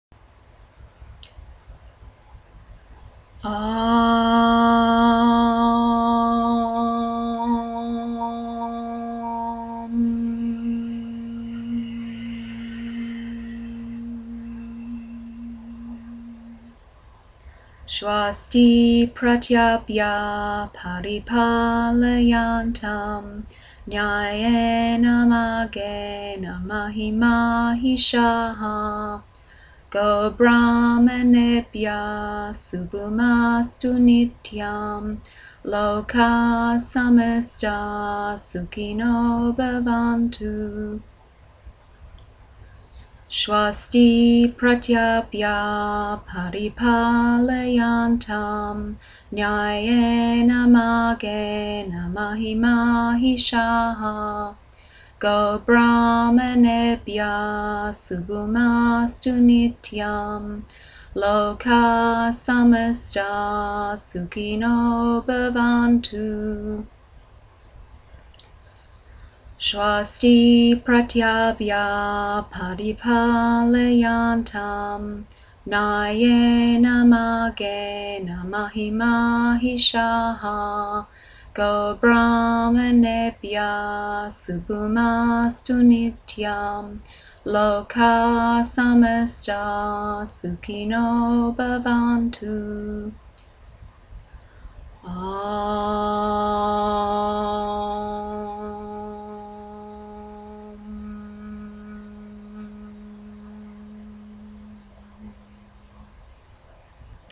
Mantra
The passages that we chant at yogaphysio are short passages taken from ancient philosophical texts called the Upanishads.